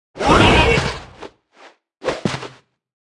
Media:Sfx_Anim_Ultimate_Hog Rider.wav 动作音效 anim 在广场点击初级、经典、高手、顶尖和终极形态或者查看其技能时触发动作的音效
Sfx_Anim_Ultra_Hog_Rider.wav